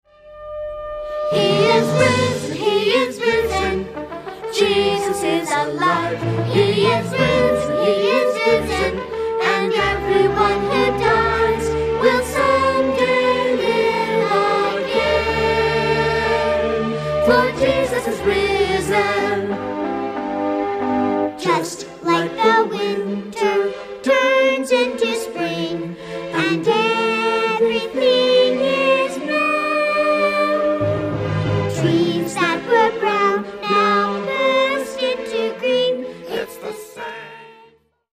Selected Song Samples